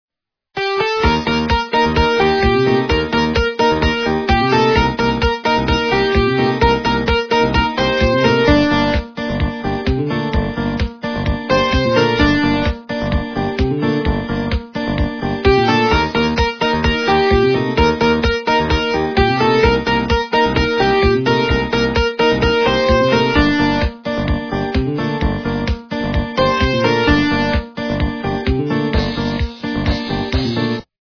- русская эстрада